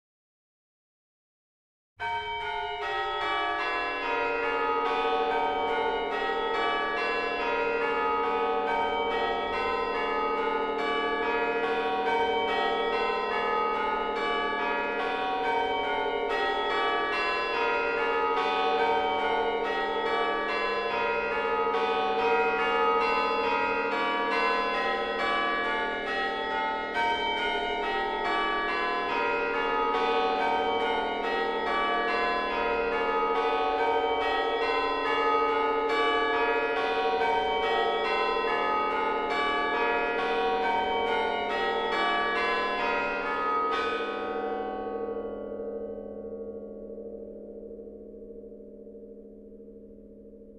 * A bell ringer – the bells are rung as the bride arrives and at the conclusion of the service
Sample of Toorak Bells
01-St-Johns-Toorak-Bells-051.mp3